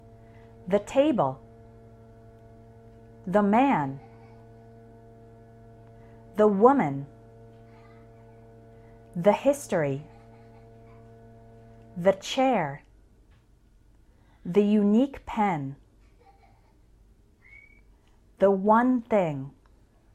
Before a consonant sound we say THUH and before a vowel sound we say THEE.
How to pronounce THE + consonant sound